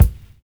DISCO 7 BD.wav